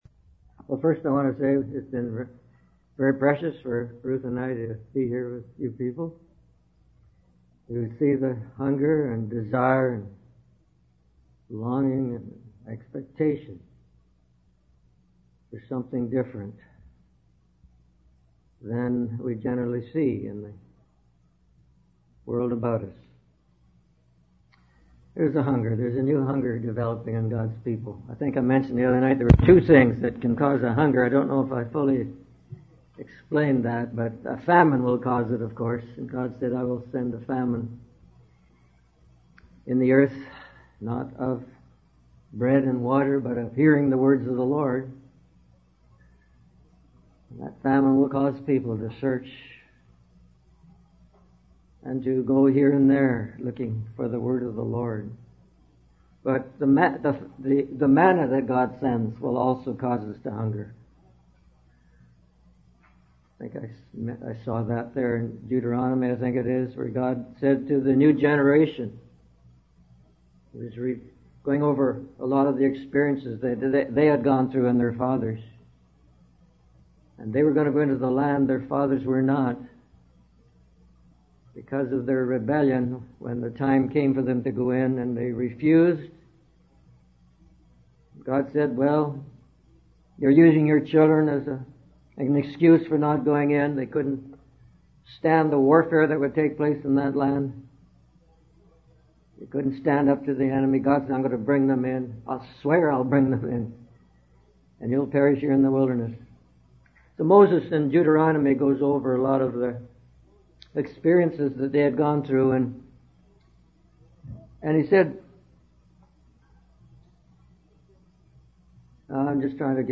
In this sermon, the preacher reflects on the Israelites' journey in the wilderness for 40 years.